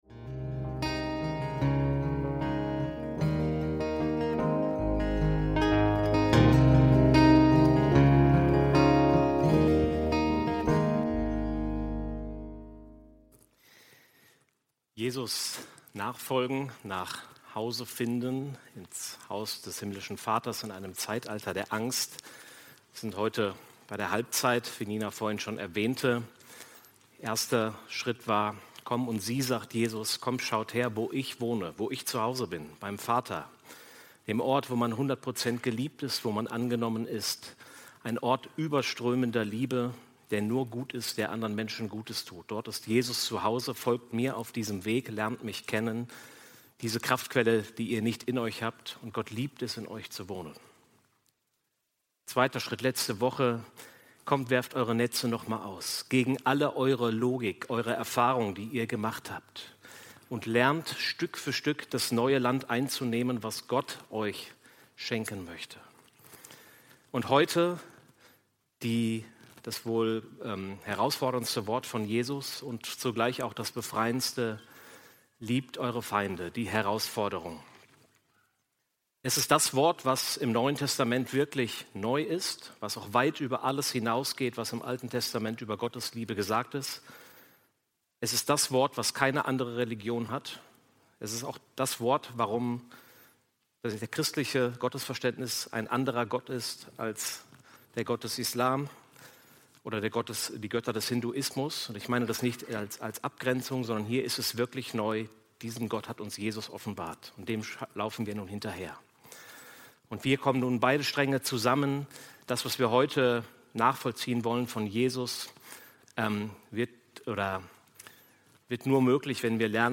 Die Herausforderung "Liebt eure Feinde" – Predigt vom 16.02.2025 ~ FeG Bochum Predigt Podcast